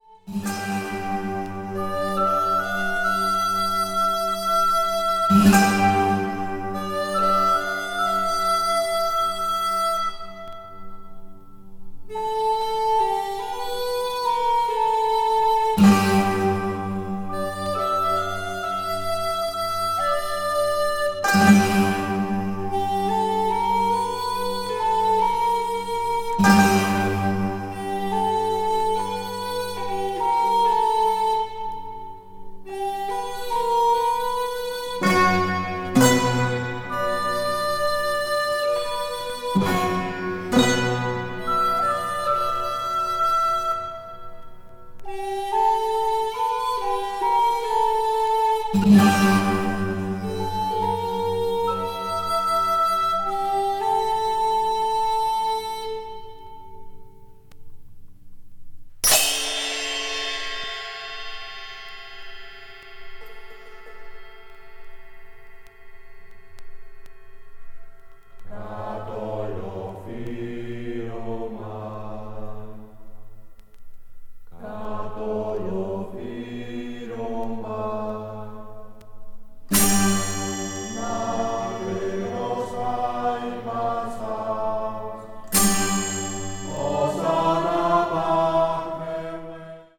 media : EX/EX(some slightly noises.)
an ancient music ensemble